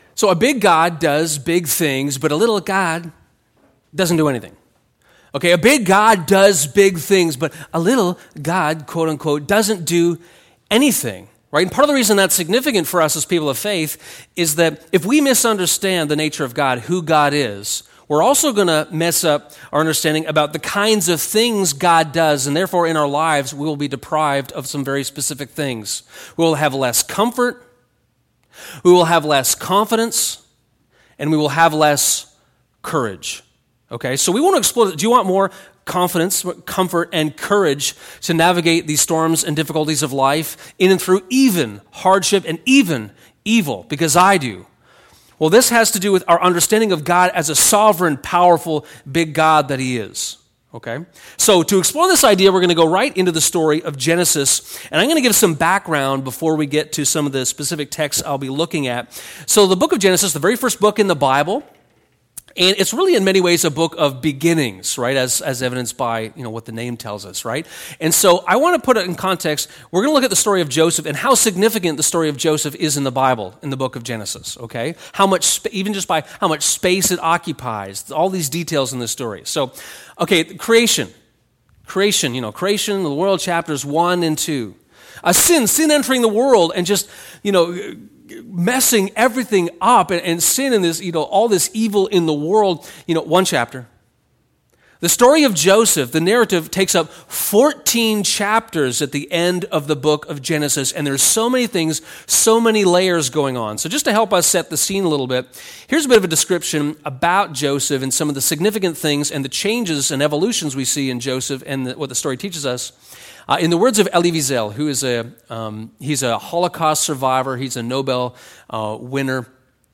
Good out of evil [Sermon]